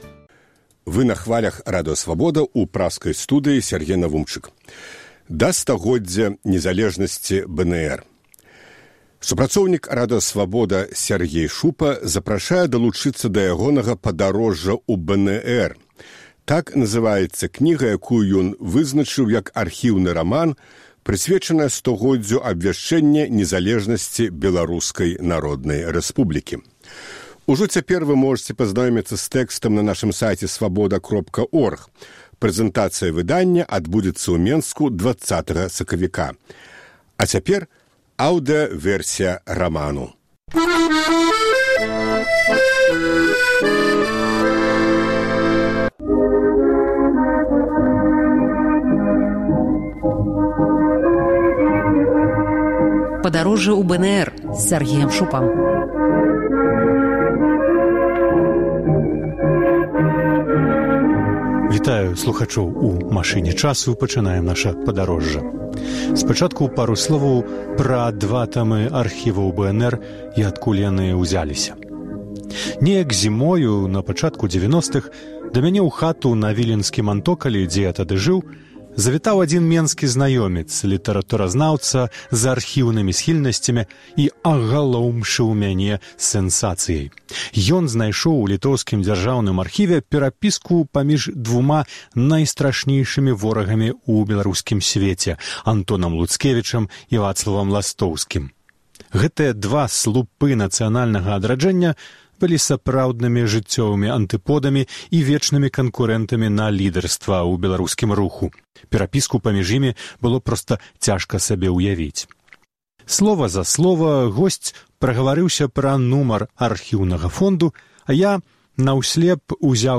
А цяпер – аўдыёвэрсія кнігі.